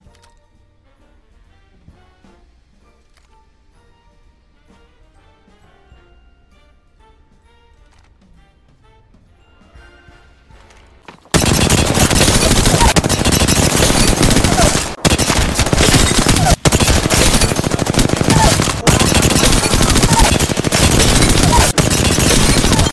Enjoy the soothing sounds of one clipping with the R-301